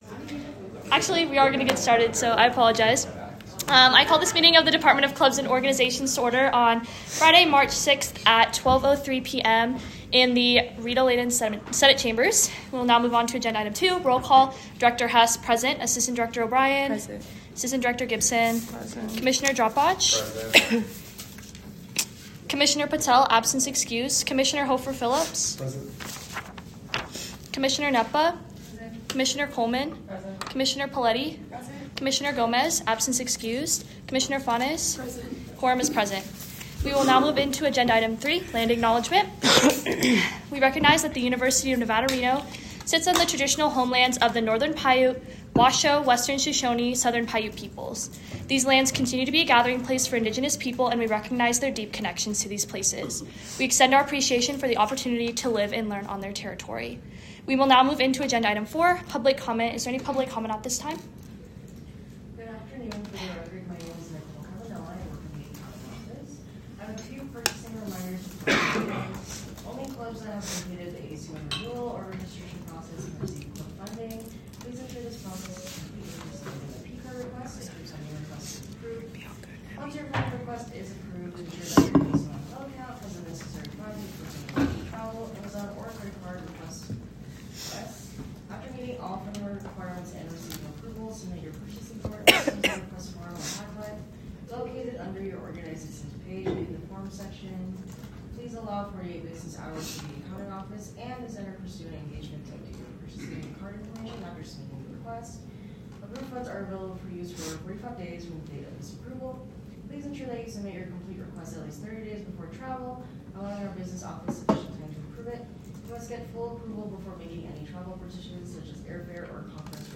Location : Rita Laden Senate Chambers - located on the third floor of the JCSU
Audio Minutes